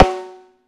Snare 16.wav